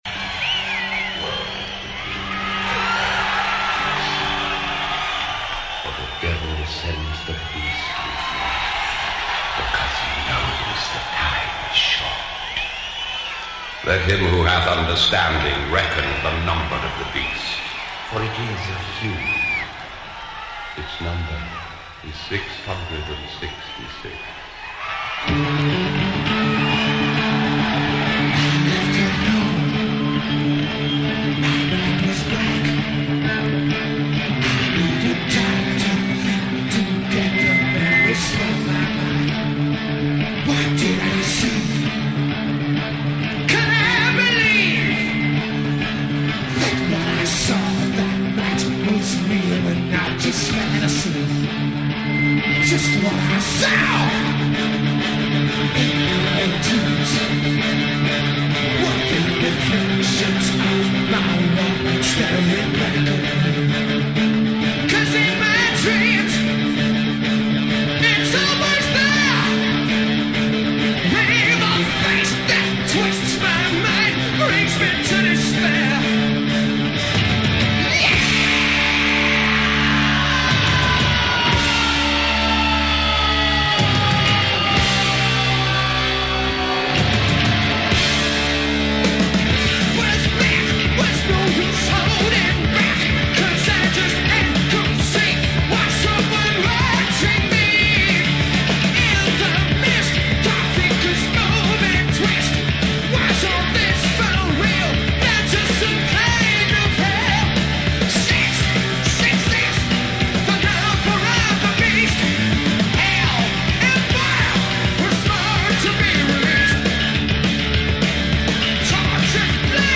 Live Concert NWOBHM